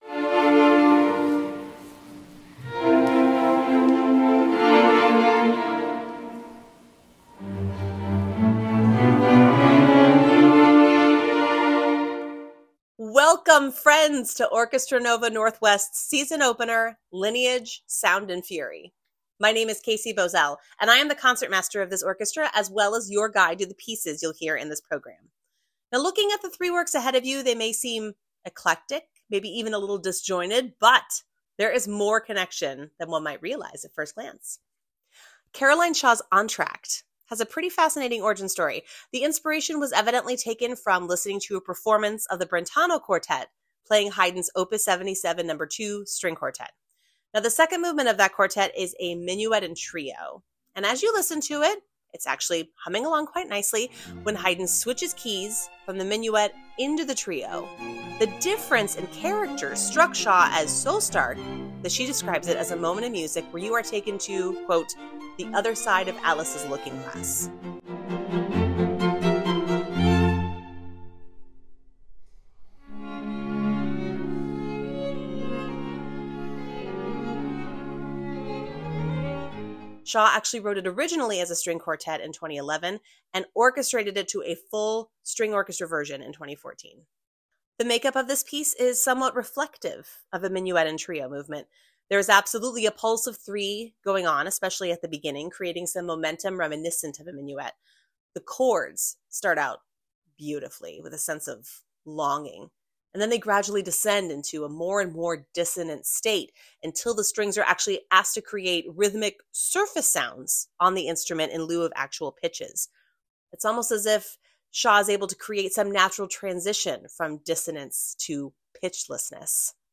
LINEAGE Pre-Concert Talk | Orchestra Nova Northwest